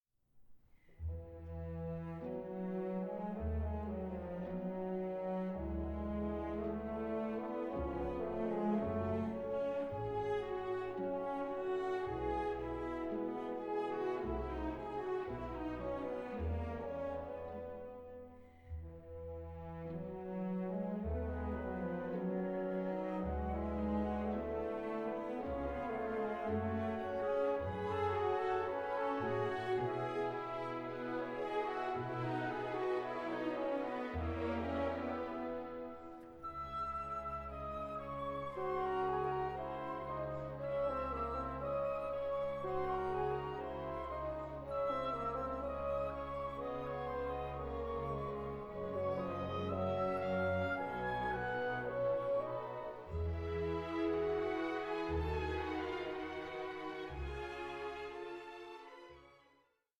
ballet in four acts